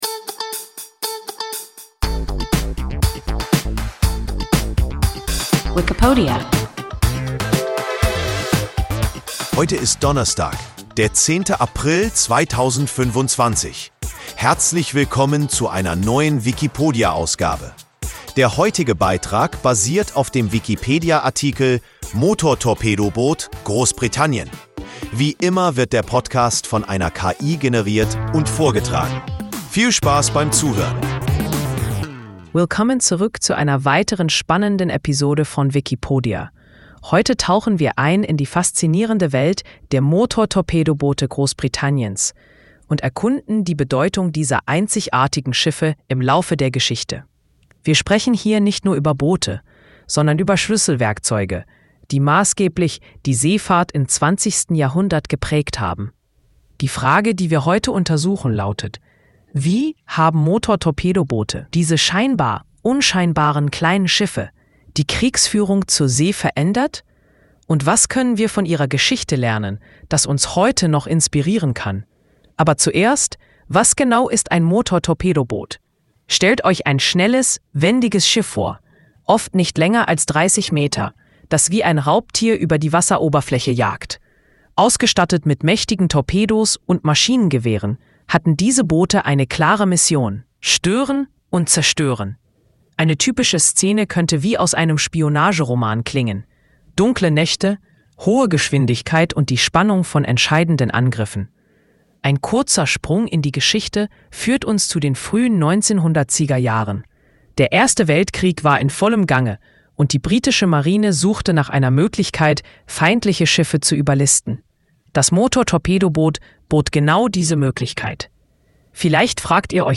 Motortorpedoboot (Großbritannien) – WIKIPODIA – ein KI Podcast